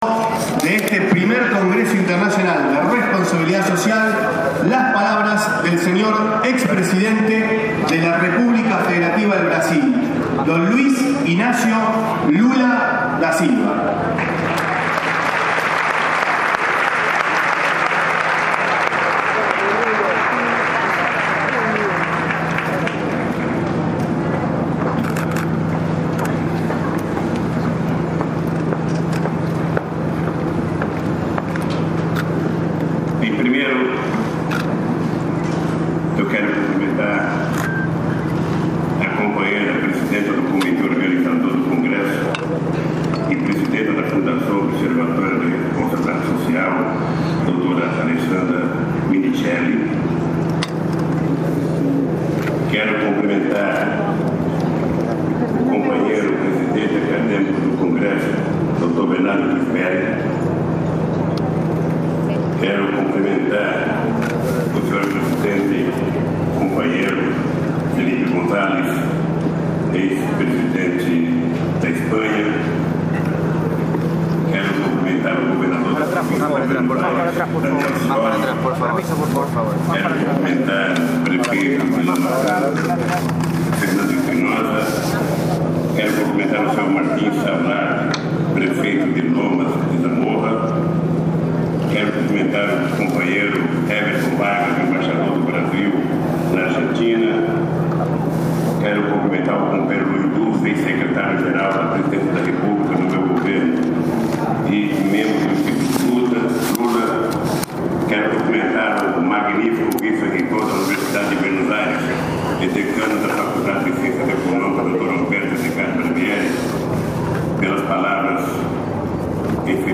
1er. Congreso Internacional de Responsabilidad Social.